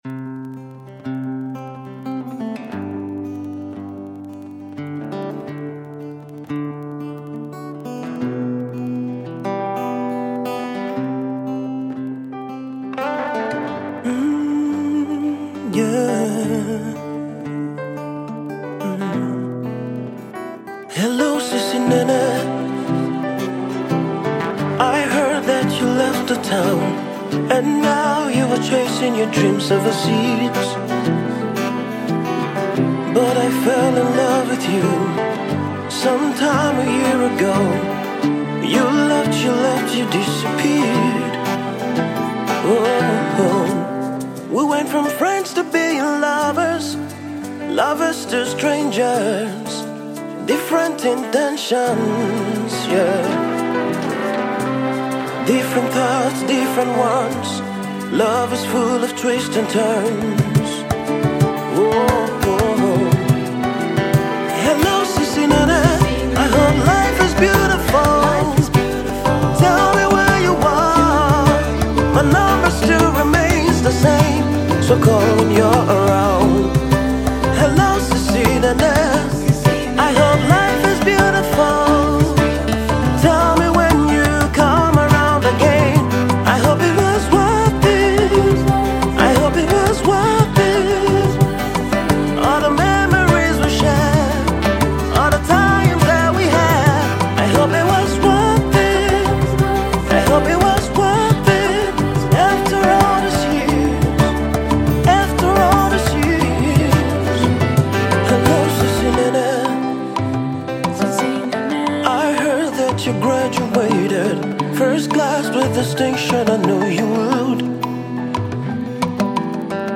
soul music